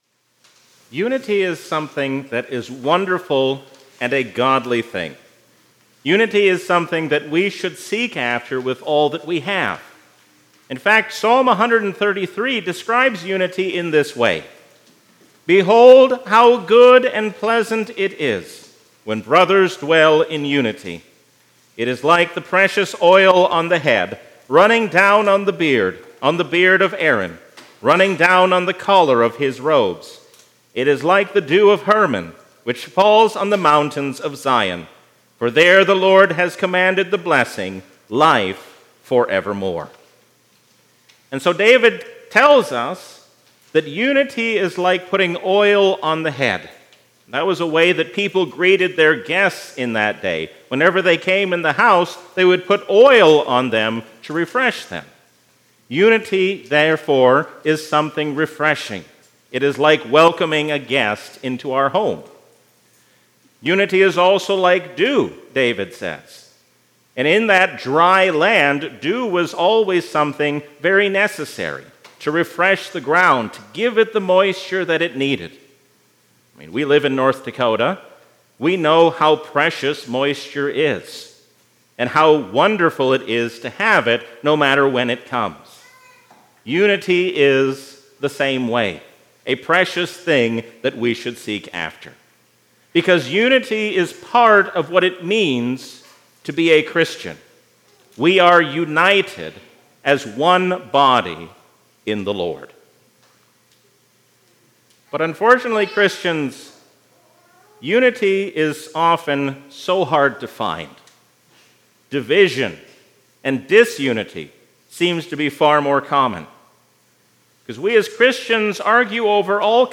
A sermon from the season "Trinity 2024."